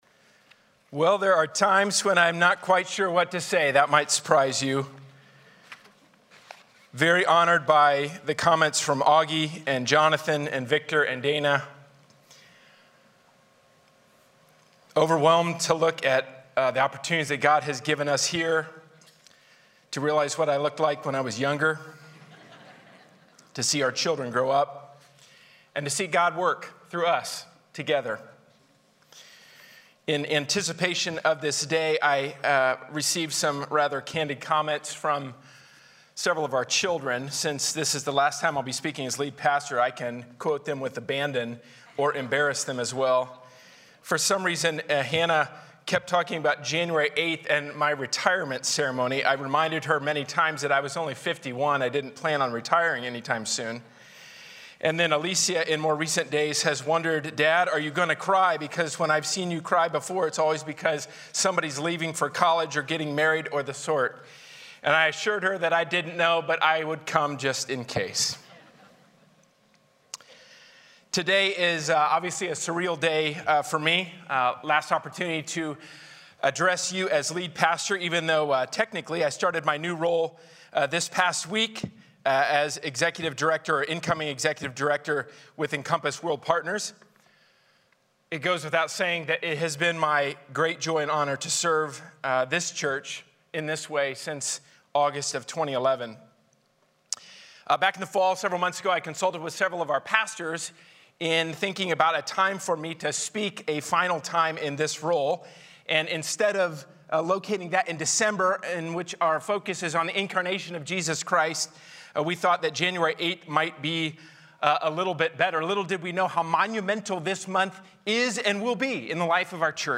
A sermon from the series "A New Start." The choice to follow Jesus for who He really is enables the new start in our lives.